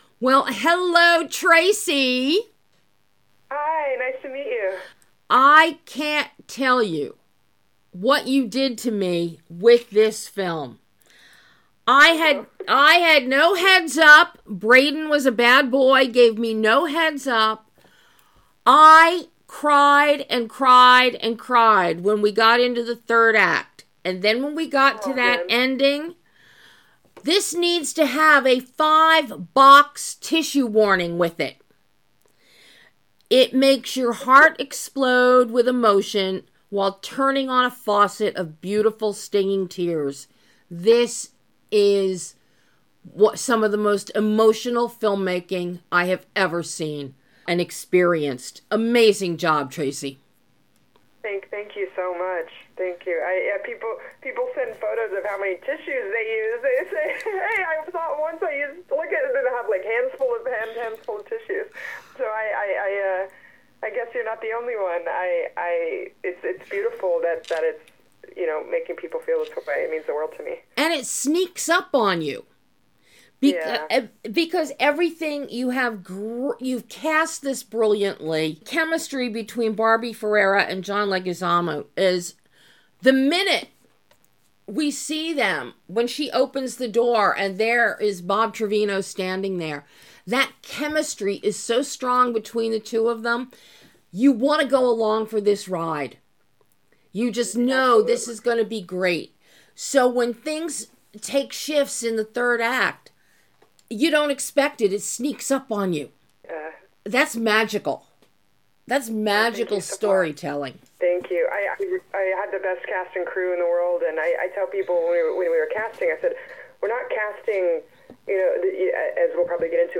- Exclusive Interview